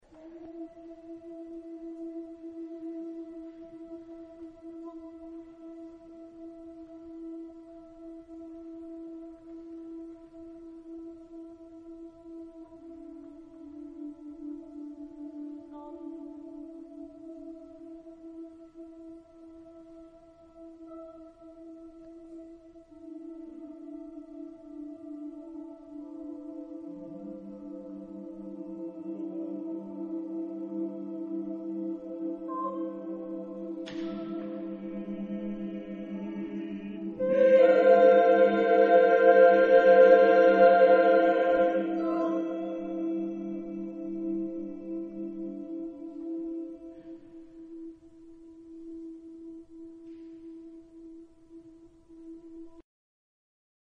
SSAATTBB (8 voces Coro mixto) ; Partitura general.
Profano. contemporáneo.